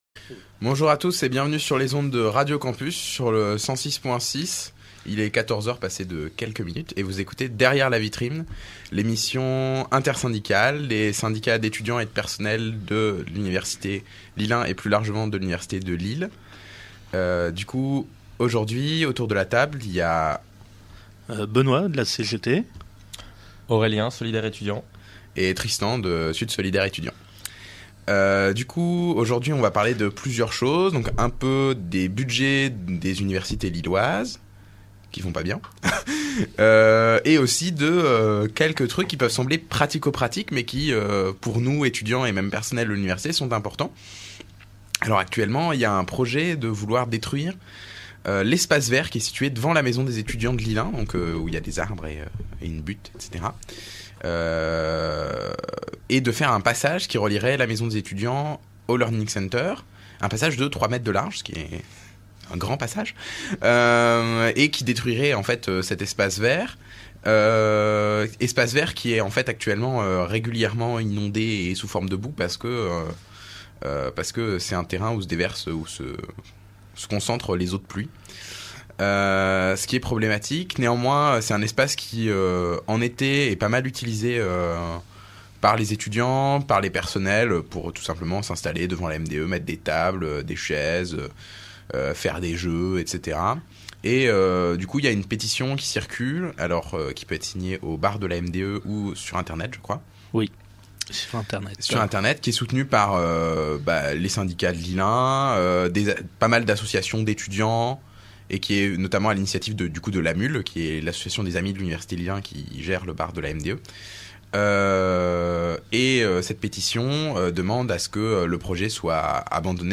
« Derrière la Vitrine », c’est l’émission des syndicats (étudiants et personnels) de l’université Lille1, sur Radio Campus Lille (106,6 FM), tous les jeudis, de 14h à 15h. On y parle de l’actualité universitaire et des luttes sociales.